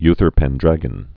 (ythər pĕn-drăgən, -)